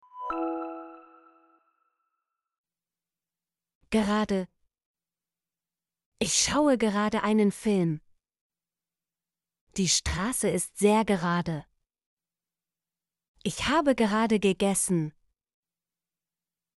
gerade - Example Sentences & Pronunciation, German Frequency List